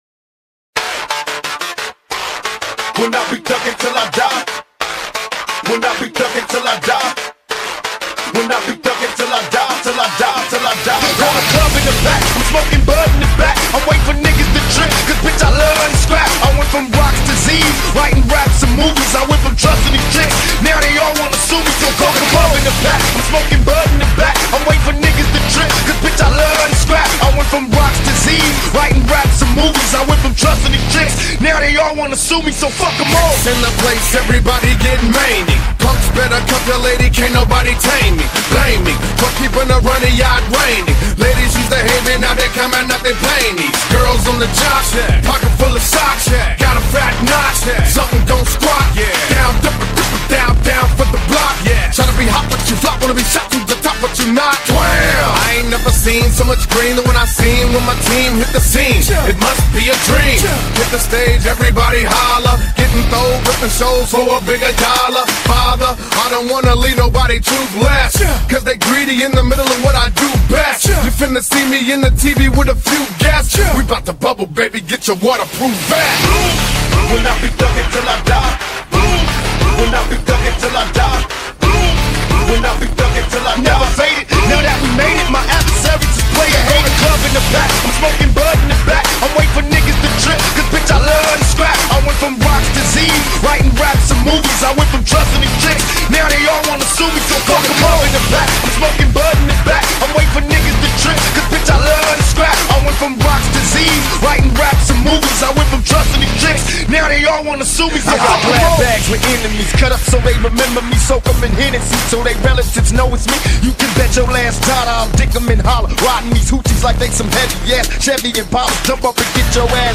ریمیکس
آهنگ رپ